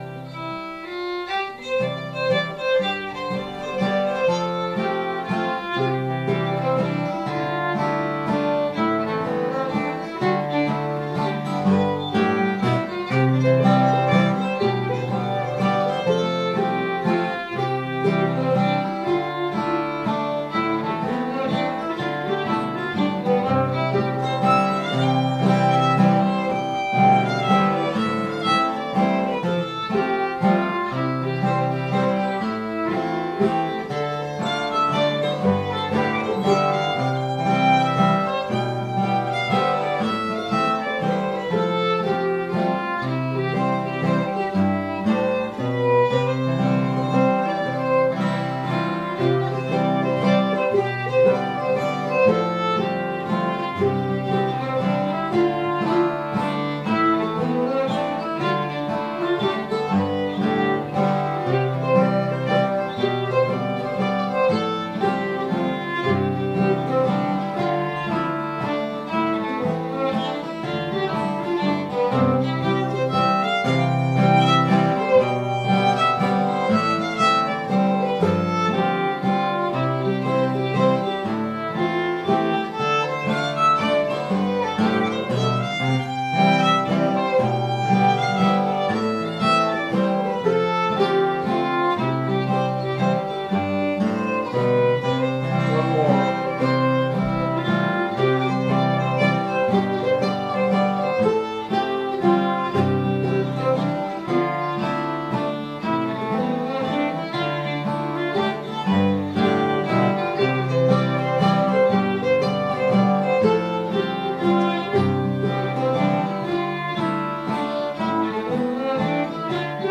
Here's a sample of every tune that has been recorded at the Pegram Jam
Most of the recordings should be clear enough to be useful for learning melodies and for practice accompaniment.